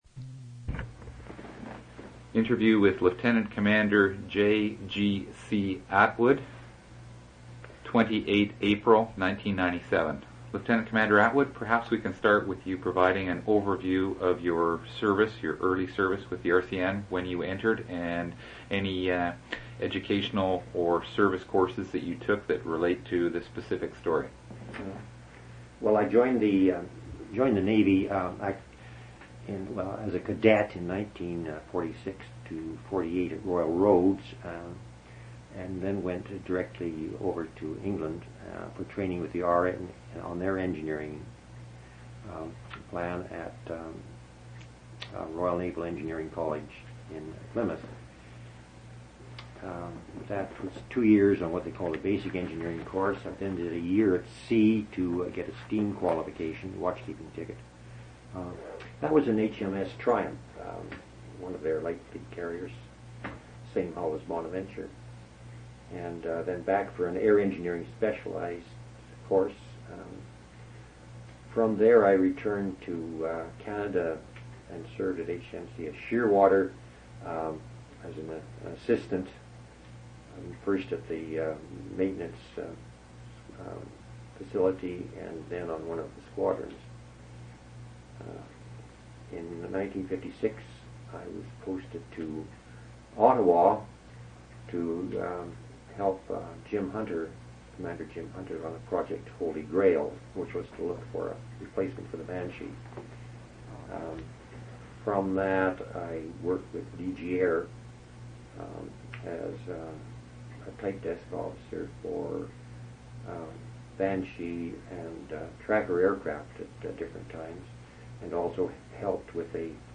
Two original audio cassettes in Special Collections.
oral histories (literary genre) interviews reminiscences